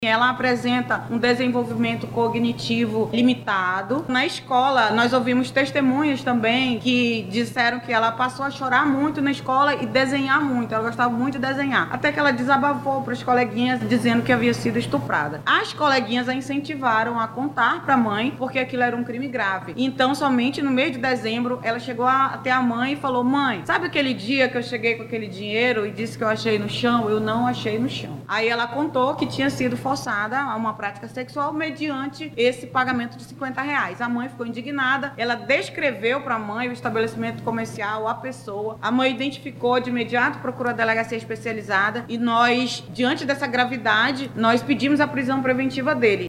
Ainda segundo a delegada, o caso foi descoberto após a menor apresentar mudança de comportamento e ser encorajada a denunciar o crime.